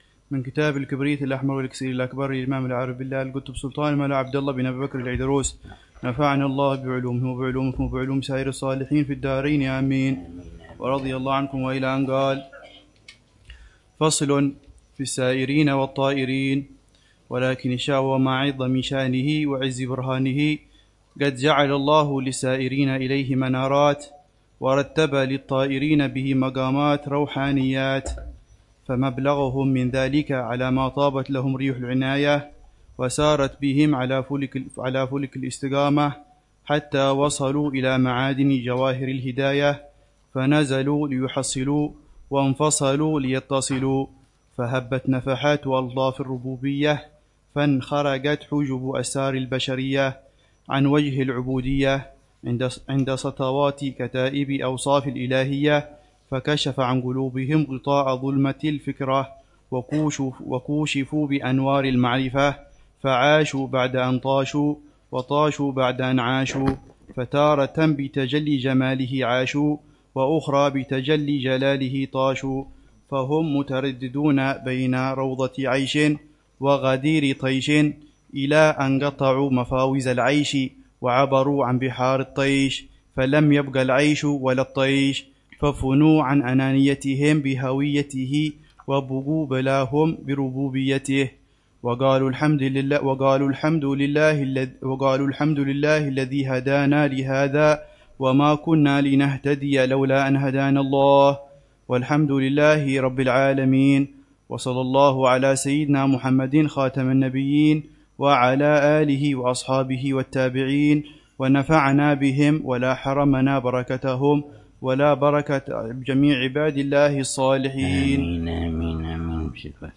الدرس السابع عشر للعلامة الحبيب عمر بن محمد بن حفيظ في شرح كتاب: الكبريت الأحمر و الأكسير الأكبر في معرفة أسرار السلوك إلى ملك الملوك ، للإمام